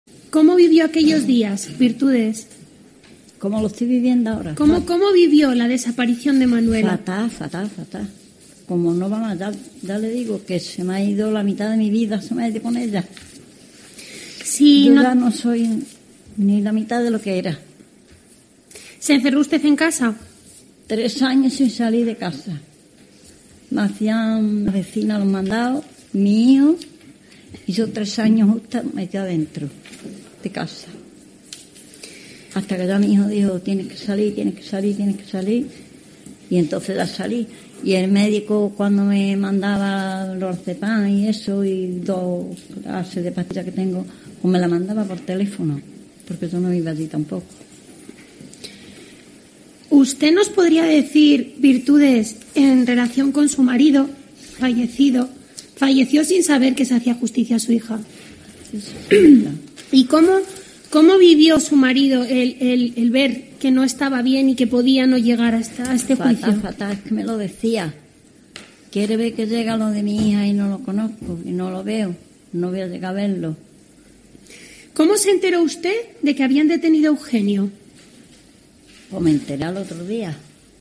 Durante su declaración, al borde del llanto en todo momento